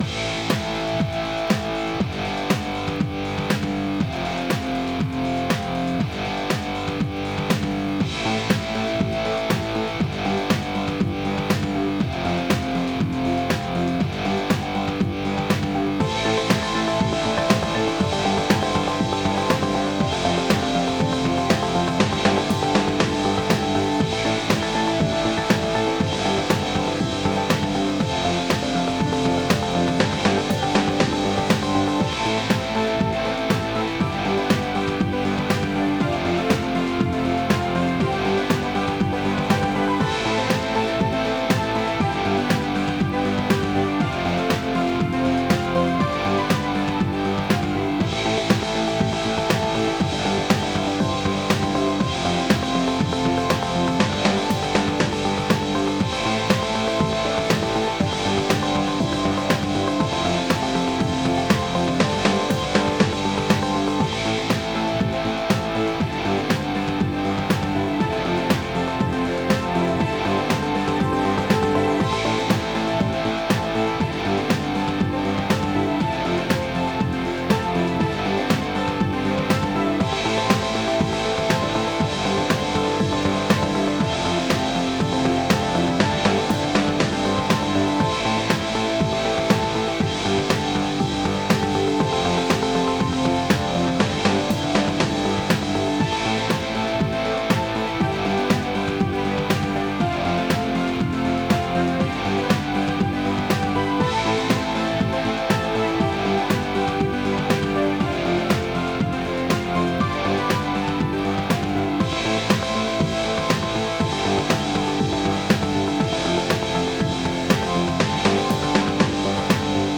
Some kinda groovy retro synth rock for cut scenes or something..